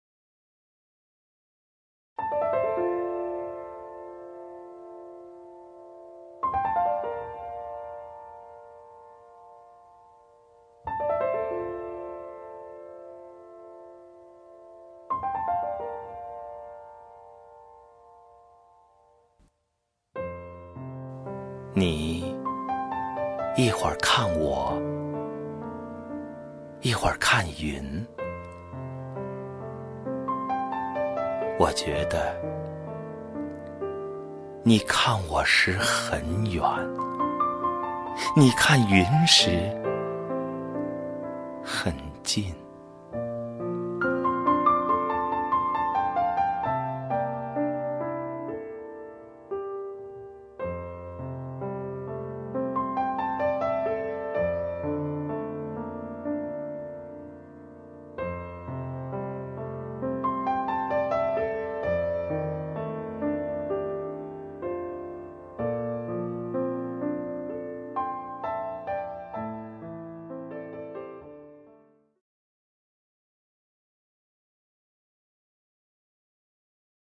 赵屹鸥朗诵：《远和近》(顾城) 顾城 名家朗诵欣赏赵屹鸥 语文PLUS